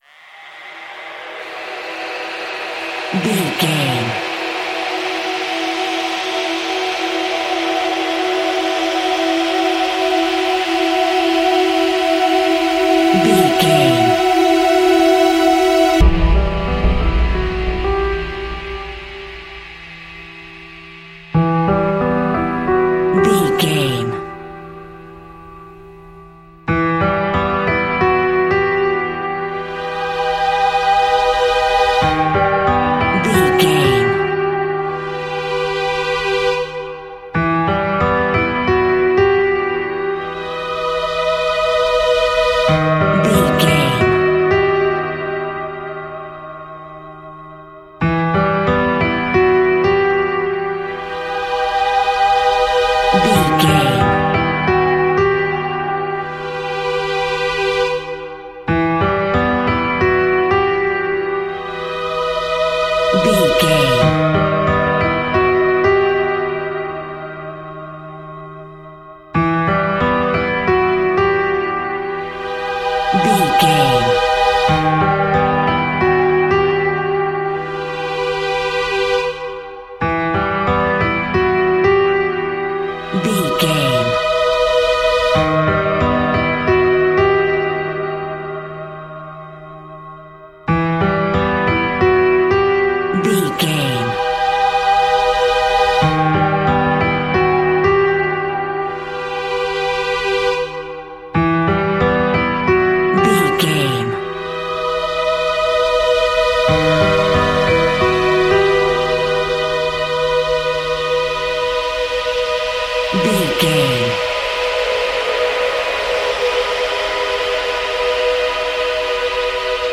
In-crescendo
Aeolian/Minor
Slow
scary
ominous
dark
suspense
haunting
eerie
piano
strings
horror
creepy